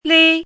怎么读
li
li1.mp3